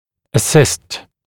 [ə’sɪst][э’сист]помогать, содействовать; ассистировать